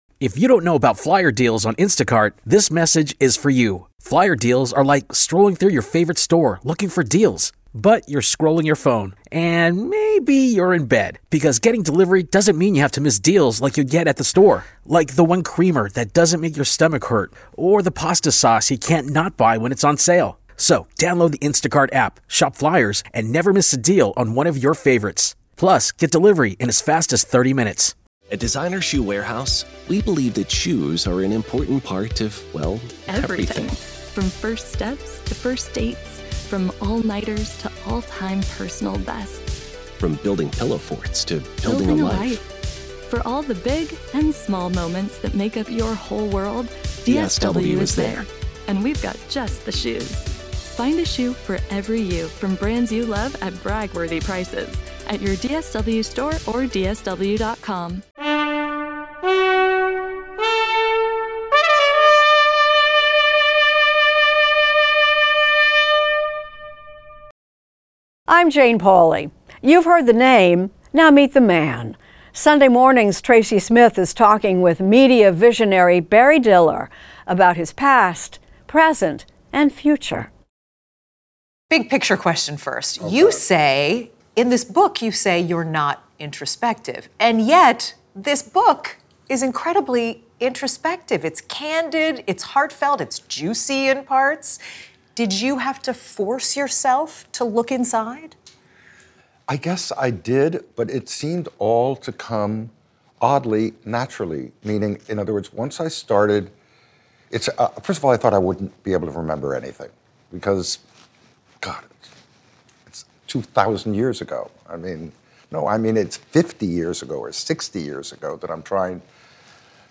Extended Interview: Barry Diller